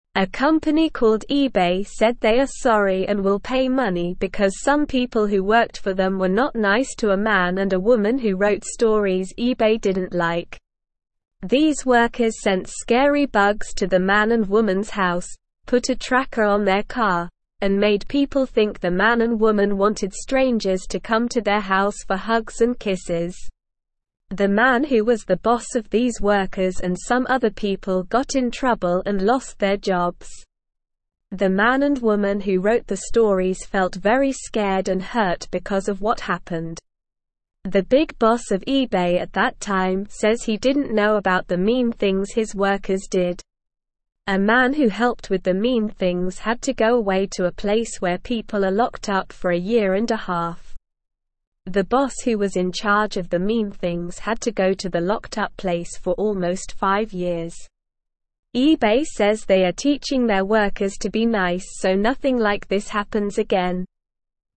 English-Newsroom-Beginner-SLOW-Reading-eBay-Sorry-for-Mean-Workers-Will-Pay.mp3